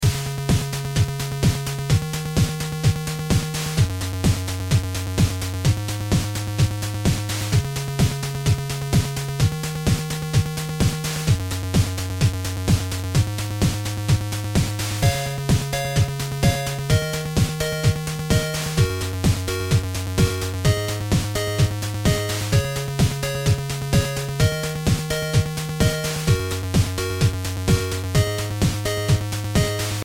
programmatically generated 8-bit musical loops